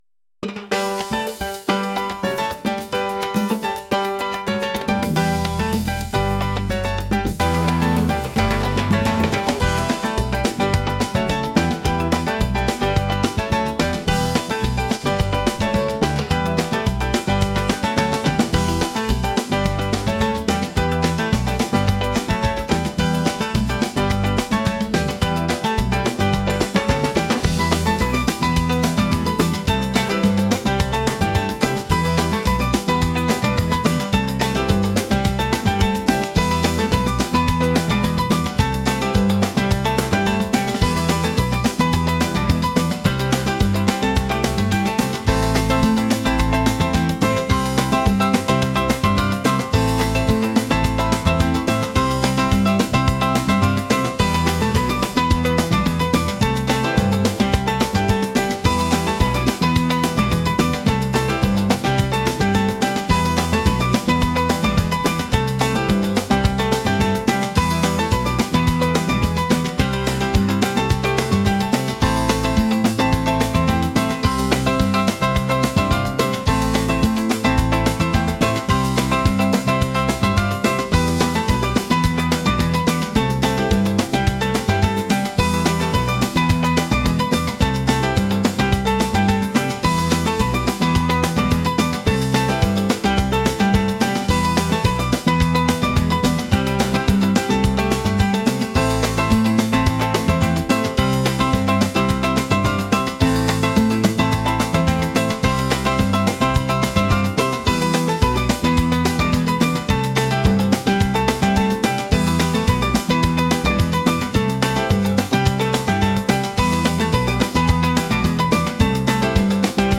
energetic | latin | pop